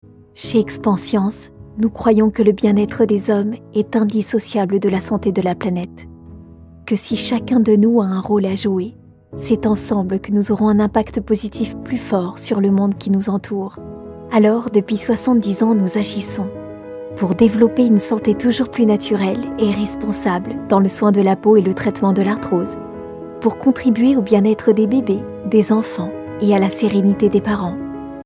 VOIX DOUCE ET TOUCHANTE